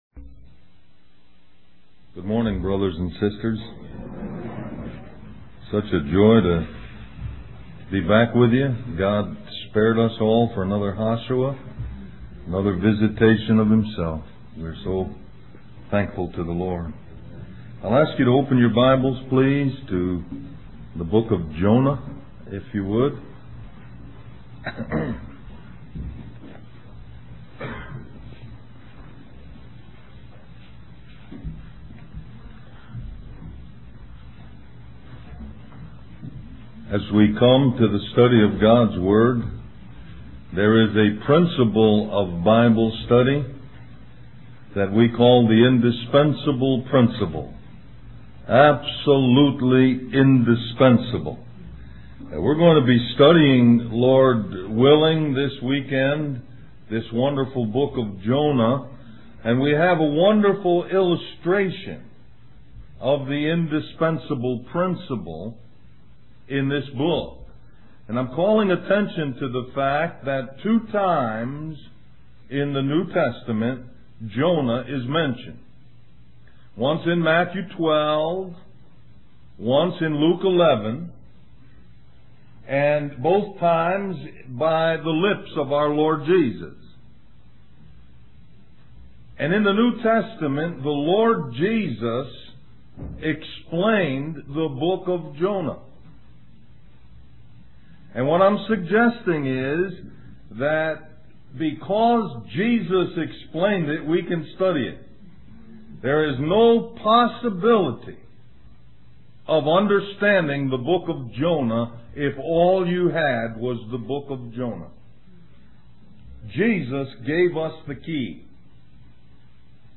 Del-Mar-Va Labor Day Retreat